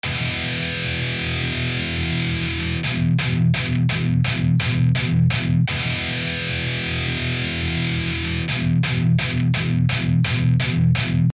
В примере сыграно Е (2- лад пятая струна и 6-я открытая), датчики Seymour Duncan TB-4, гитара Lead Star (синяя)) ).В примере с чистым звуком и с перегрузом (ну так).